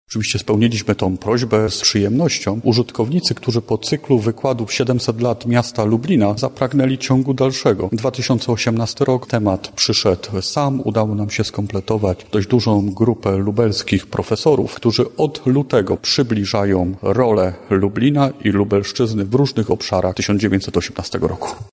wykład-o-daszyńskim-wbp-2-1.mp3